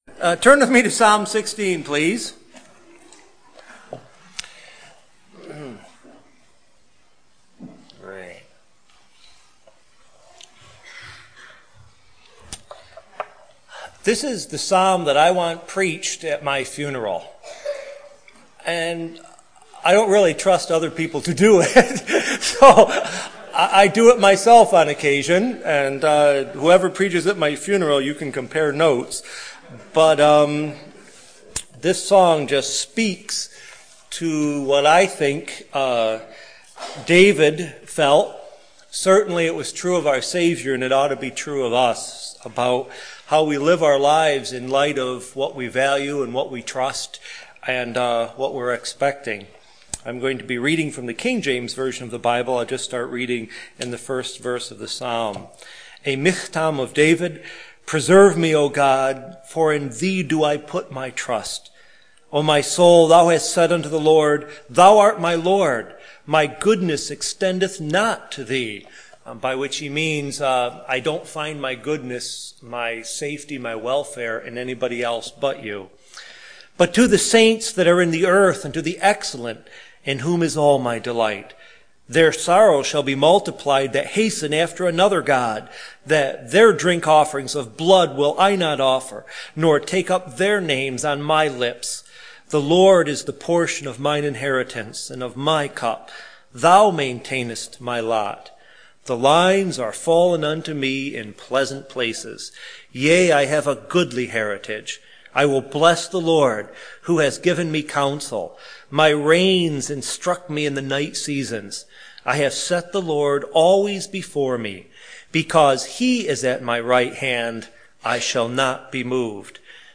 Easter Service – Psalm 16